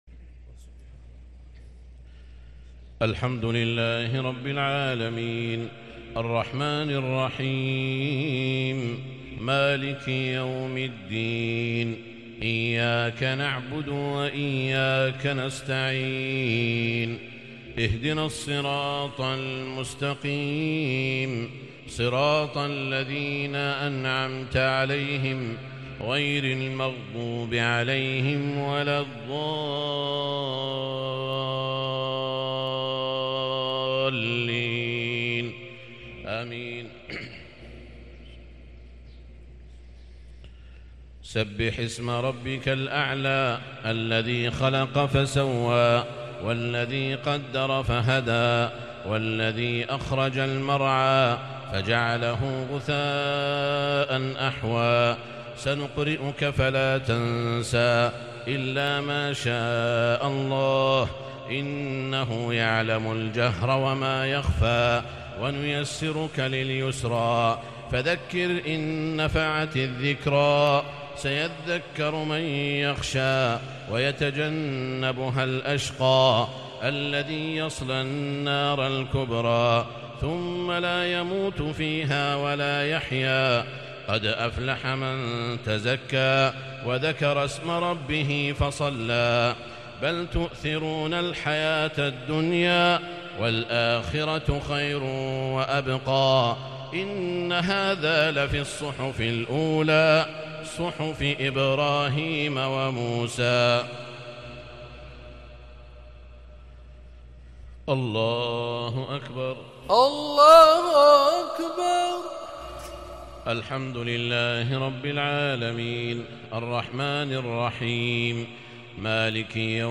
صلاة الجمعة ٧ محرم ١٤٤٤هـ سورتي الأعلى و الغاشية |Jumu’ah prayer from Surah Al-a’ala & Al-Ghashiya 5-8-2022 > 1444 🕋 > الفروض - تلاوات الحرمين